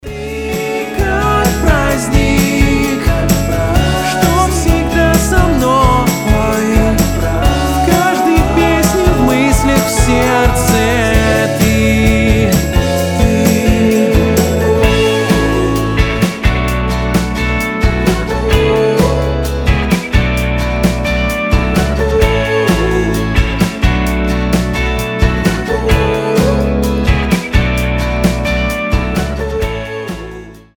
• Качество: 320, Stereo
гитара
милые
indie pop
добрые
alternative
нежные